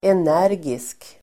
Uttal: [en'är:gisk]